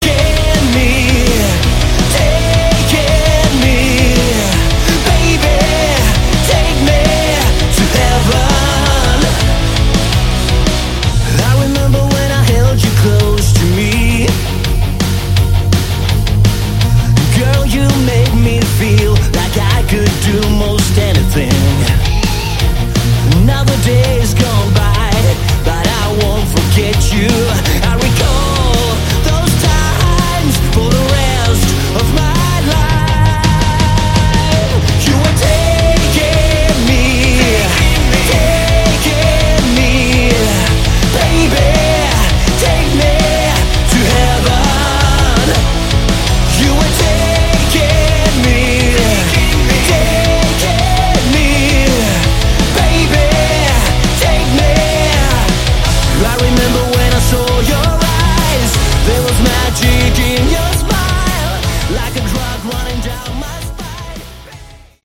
Category: Hard Rock
guitar, keyboards
vocals
bass
drums